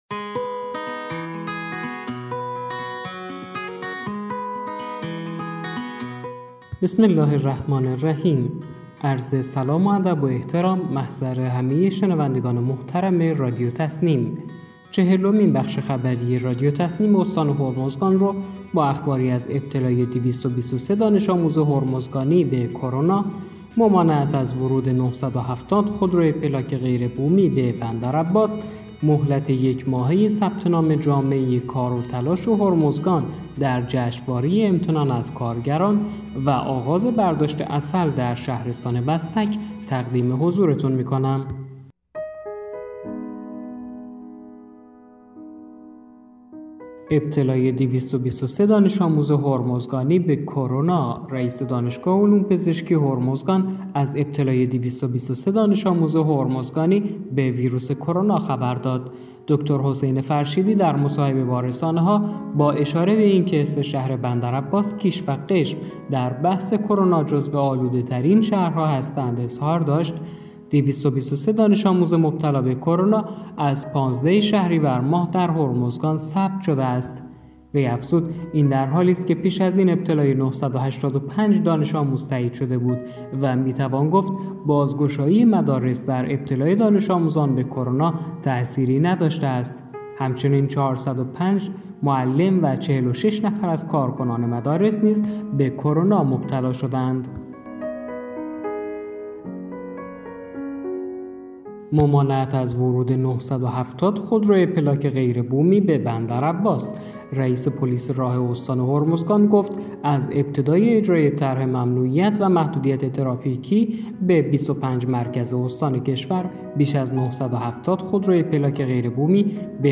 گروه استان‌ها- چهلمین بخش خبری رادیو تسنیم استان هرمزگان با بررسی مهم‌ترین اخبار این استان در 24 ساعت گذشته منتشر شد.